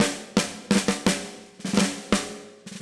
Index of /musicradar/80s-heat-samples/85bpm
AM_MiliSnareB_85-02.wav